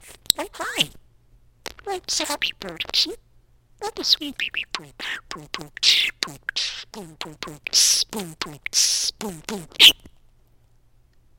Bubbles pronounces words better and sound effects free download